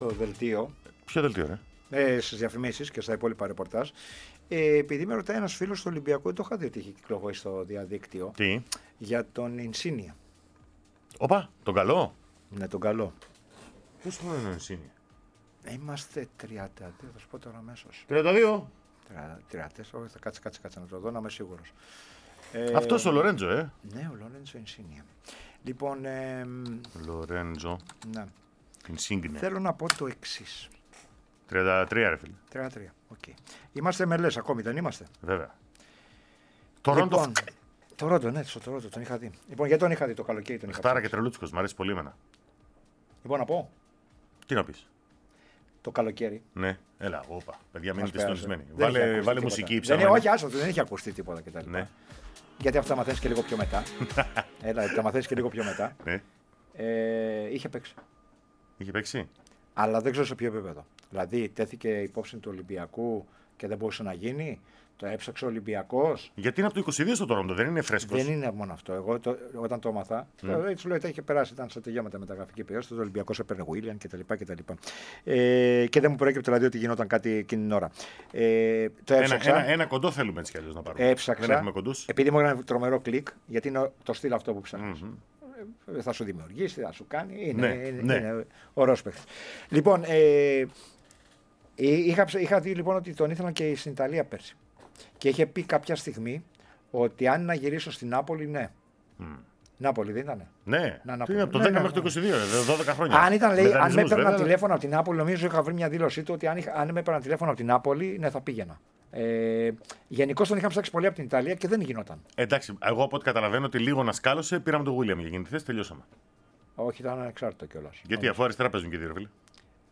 Αναλυτικά όσα μετέφερε στον αέρα της ΕΡΑ ΣΠΟΡ ο έμπειρος ρεπόρτερ: